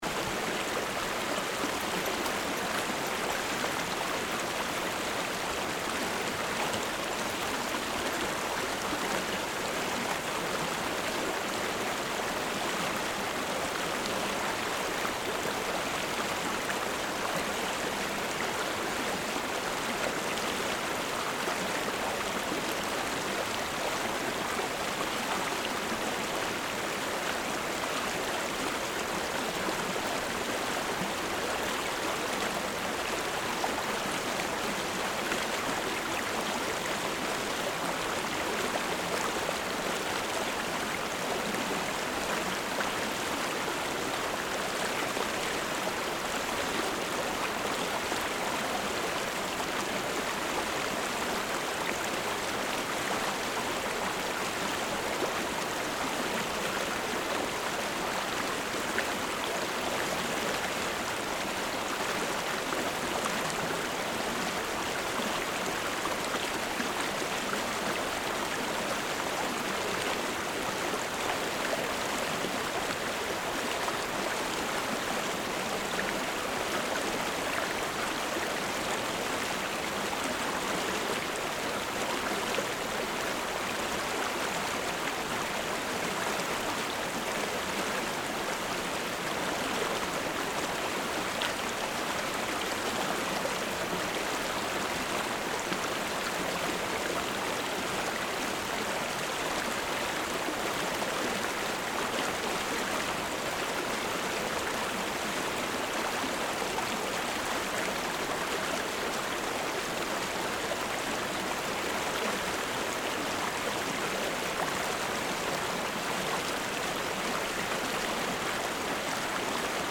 Listen to the peaceful sounds of waterfalls found only in the Columbia River Gorge.
This is a playlist of field recordings from Waterfalls found in the Columbia River Gorge. Waterfalls are create white noise which is the scattering of thousands of sounds.
Latourell Falls Creek Close, Falls in the background Field Recording
Latourell+Falls+Stream+Close+Falls+in+the+background.mp3